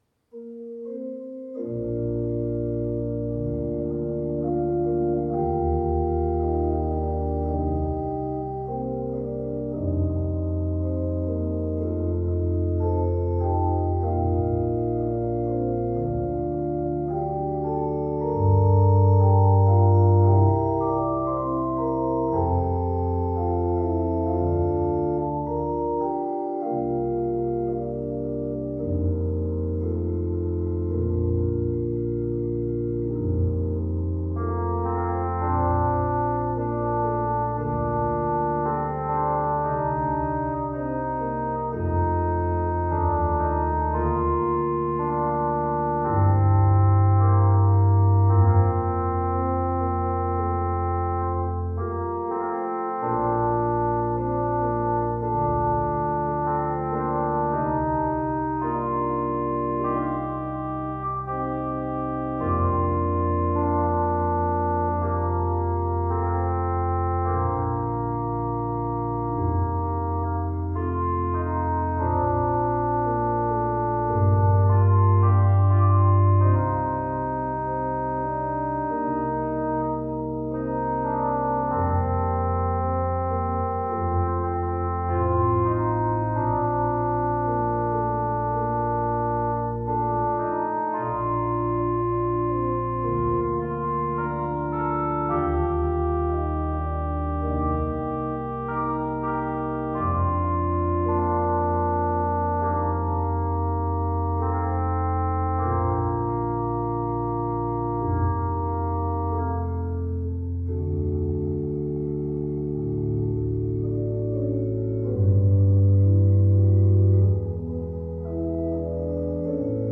Secret Prayer (Slagelse) – The Organ Is Praise
The Latter-day Saint hymn Secret Prayer is powerful reminder to pray always.